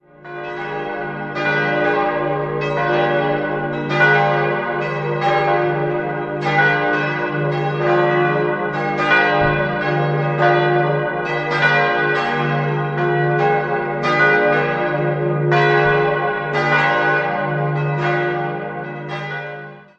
Idealquartett: dis'-fis'-gis'-h' Alle Glocken wurden 1954 von Grassmayr in Innsbruck gegossen.